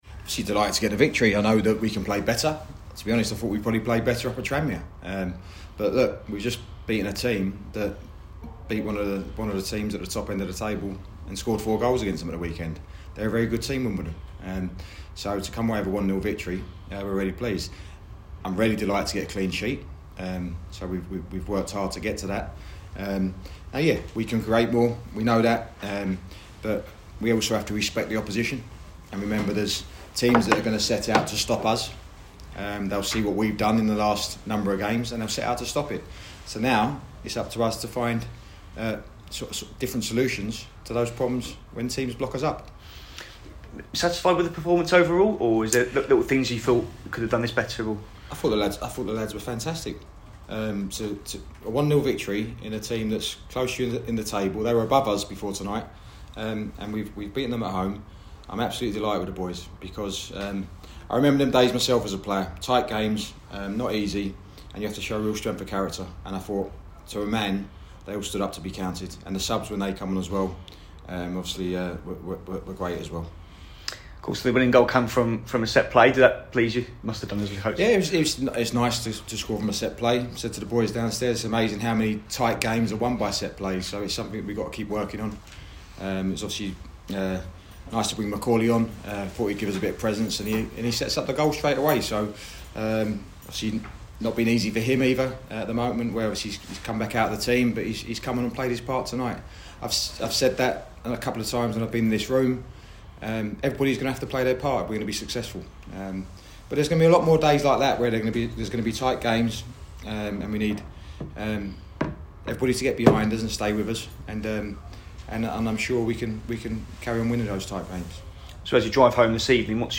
LISTEN: Gillingham manager Stephen Clemence spoke to reporters after their 1-0 victory over AFC Wimbledon - 29/11/2023